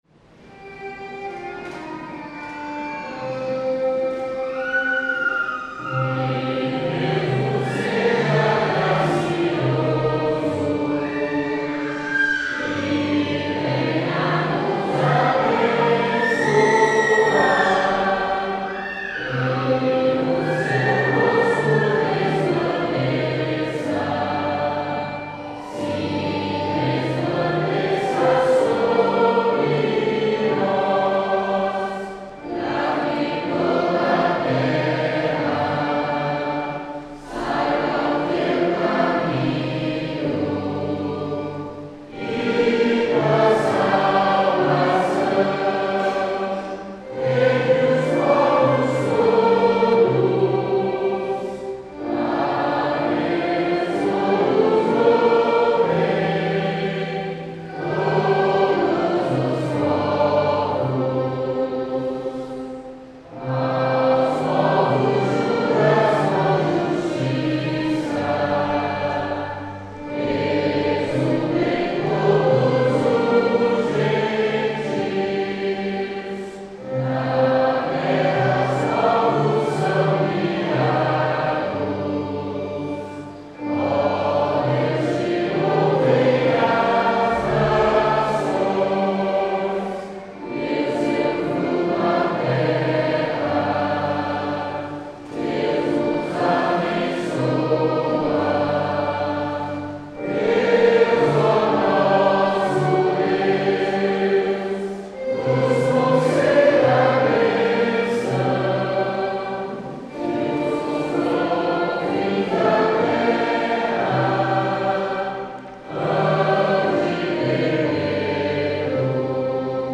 Modo: dórico
salmo_67A_cantado.mp3